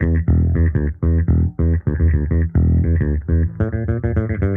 Index of /musicradar/sampled-funk-soul-samples/105bpm/Bass
SSF_JBassProc1_105E.wav